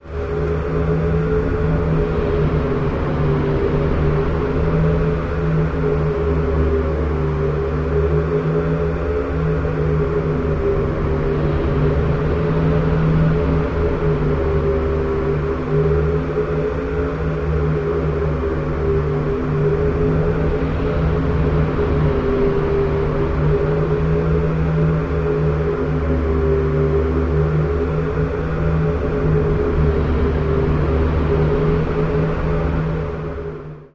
Rotary gentle rising/falling, menacing-like moans